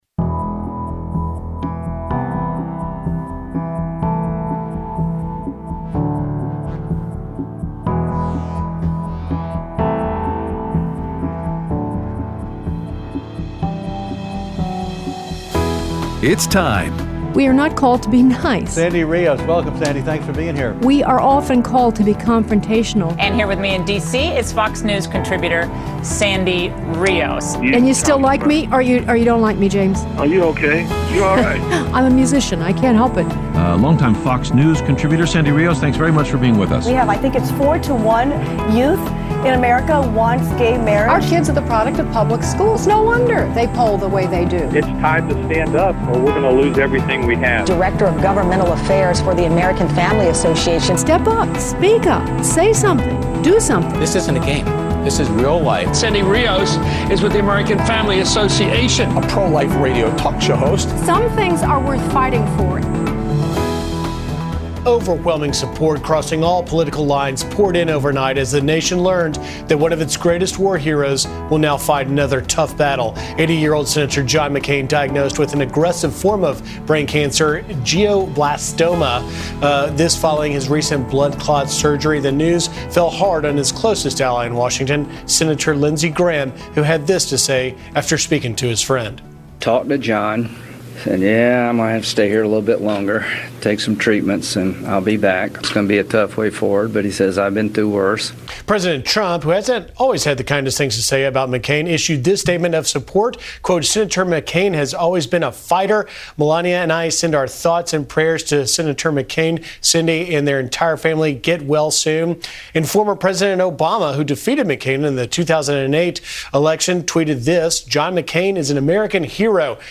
Aired Thursday 7/20/17 on AFR 7:05AM - 8:00AM CST